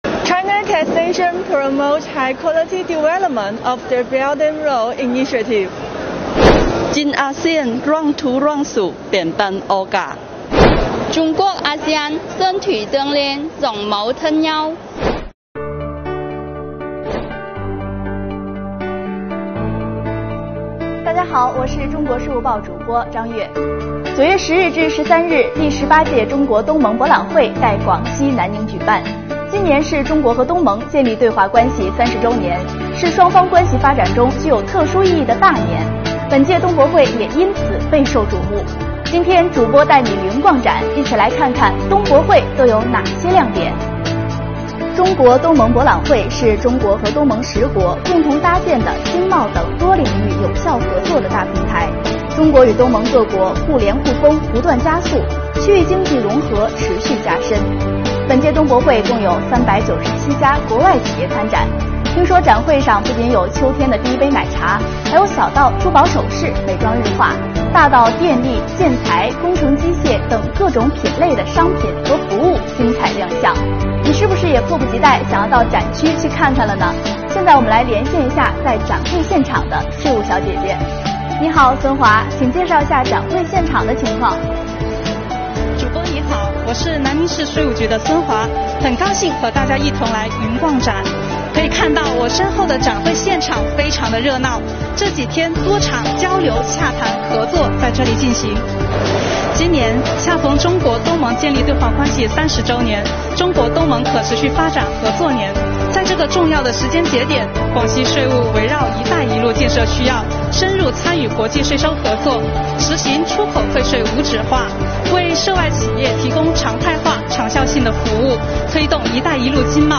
9月10日-13日，第18届中国—东盟博览会和中国—东盟商务与投资峰会在广西南宁举办。